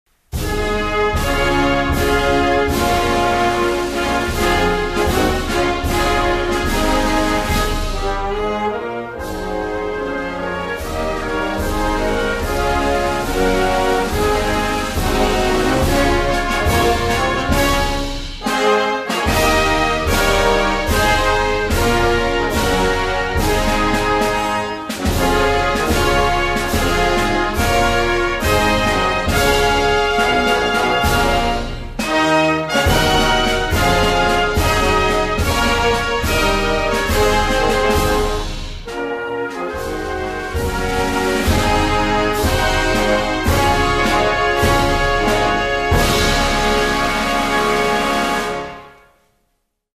North_Macedonia's_national_anthem_(instrumental).mp3